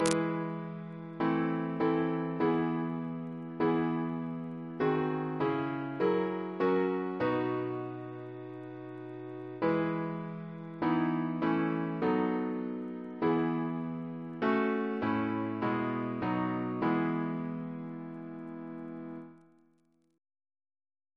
Double chant in E Composer: Percy Jackman Reference psalters: ACP: 141; H1940: 635